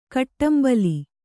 ♪ kaṭṭambali